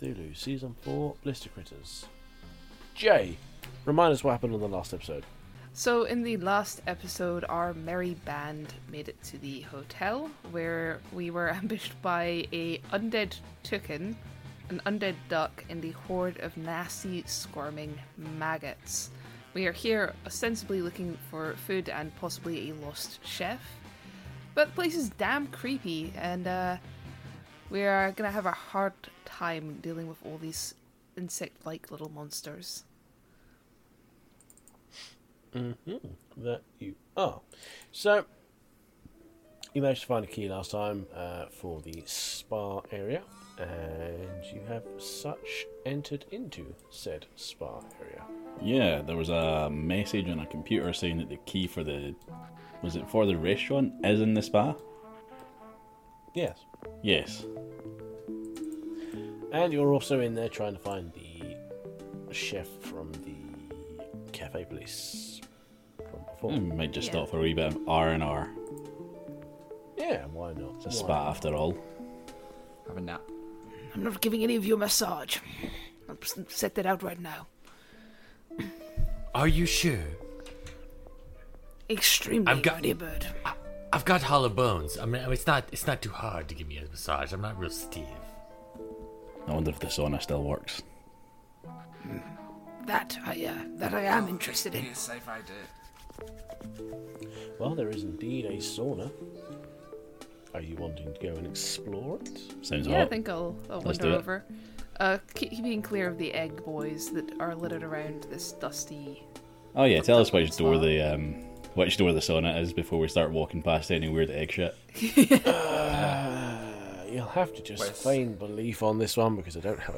combat music